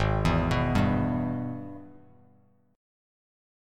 AbmM7#5 Chord